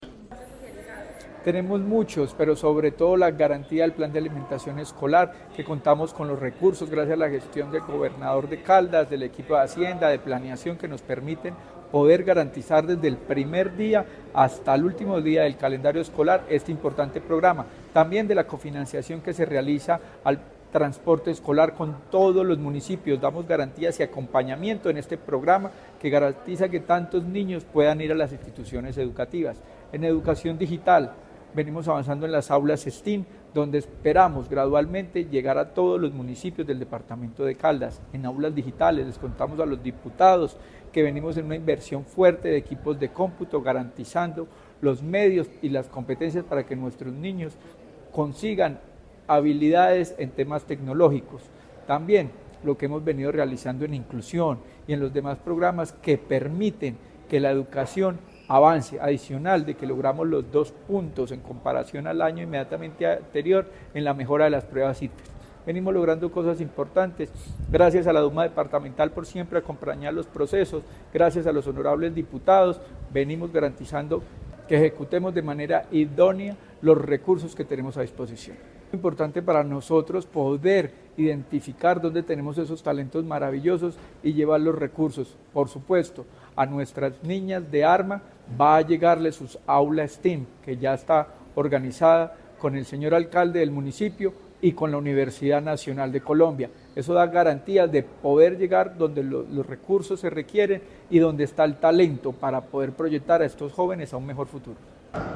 Secretario de Educación de Caldas, Luis Herney Vargas Barrera.
Secretario-de-Educacion-Luis-Herney-Vargas-informe-de-gestion-Asamblea.mp3